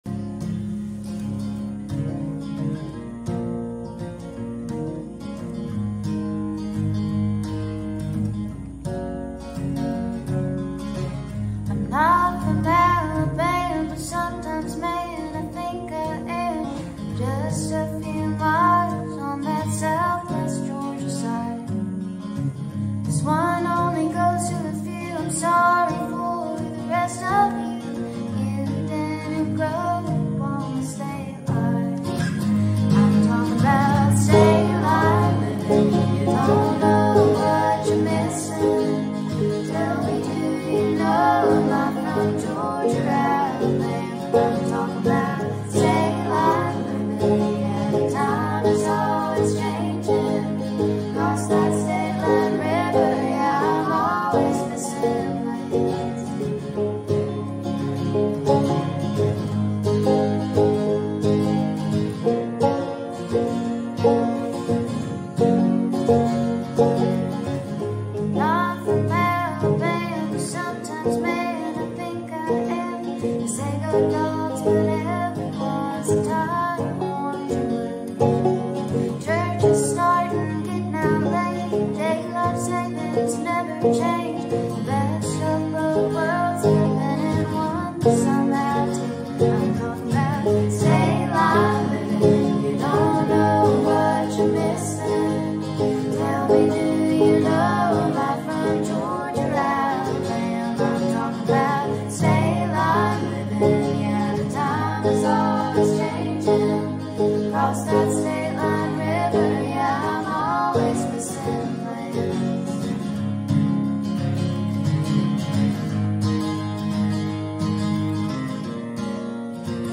country band